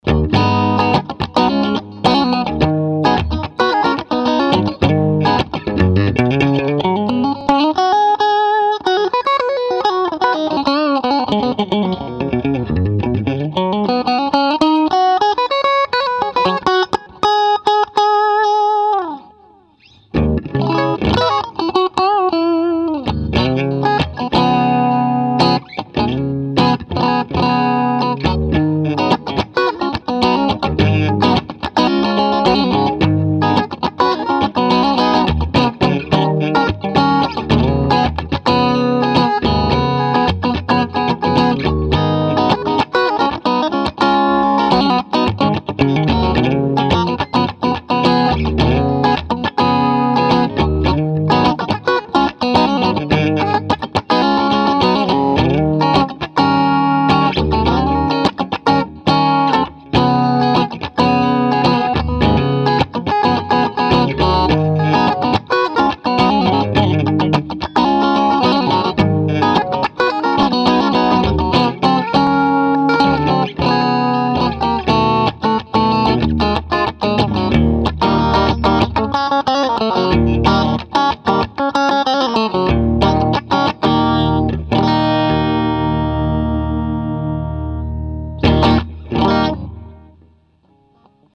Clean as it will get with a Strat. TMB 12oclock. Tube rectifier.
plexi_combo_clean.mp3